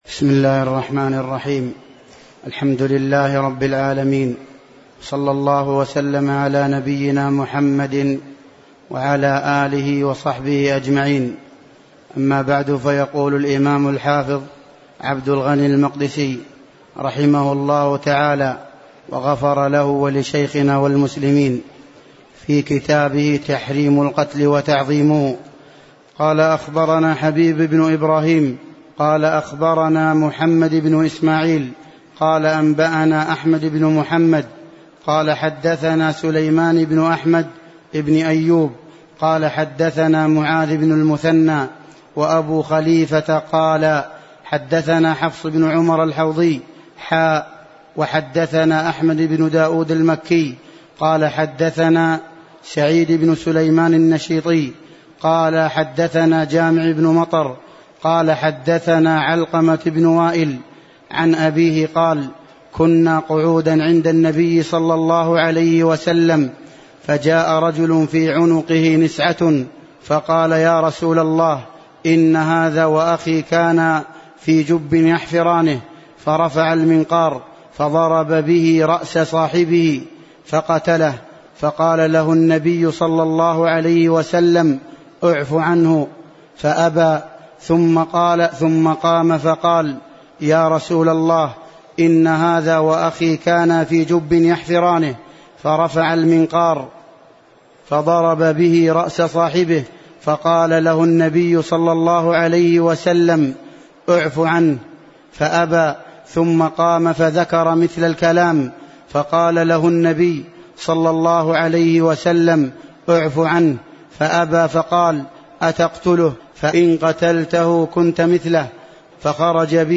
سلسلة محاضرات صوتية، وفيها شرح الشيخ